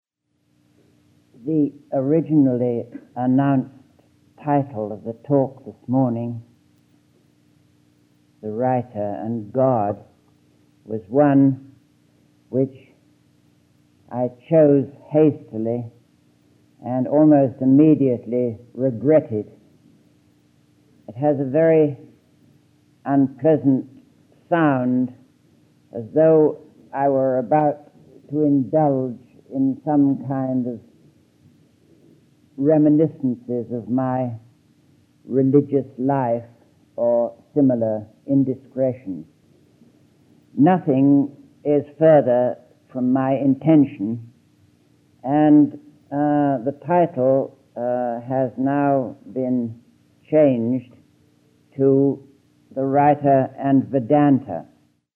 [8] An example of Isherwood’s typical self-deprecating style, his introduction to the lecture The Writer & Vedanta, sound clip is here: